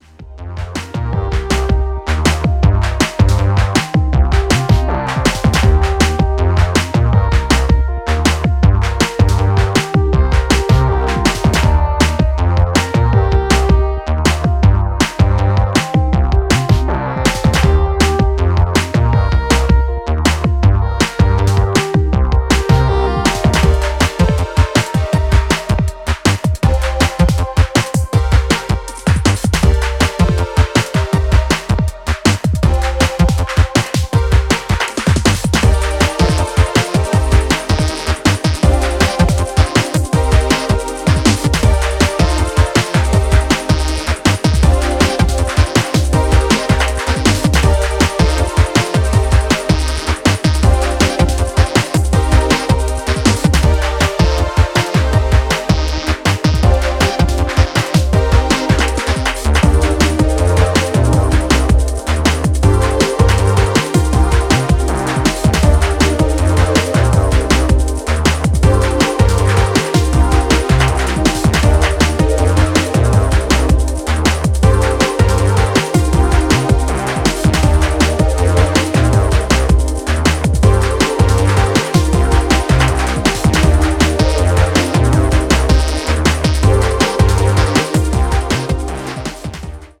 sublime electro EPs